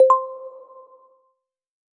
success.wav